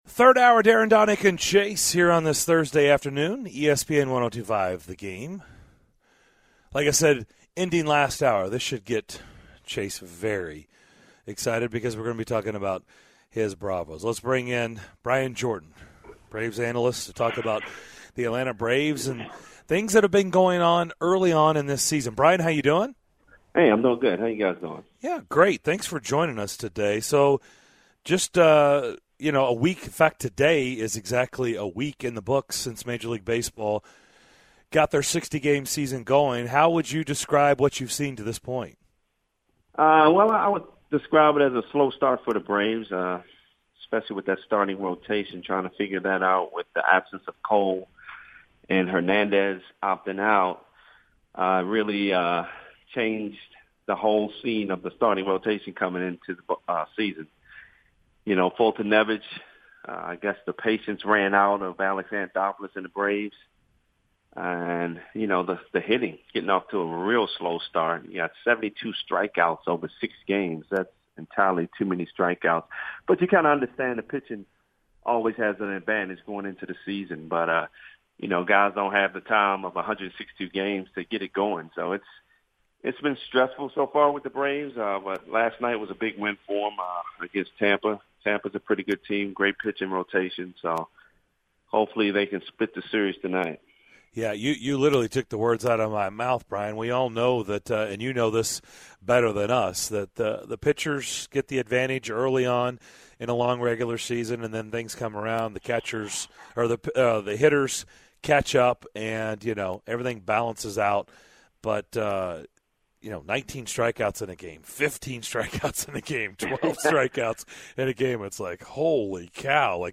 Former Atlanta Brave outfielder and current Braves analyst Brian Jordan joined DDC to discuss the Braves start to their 2020 season!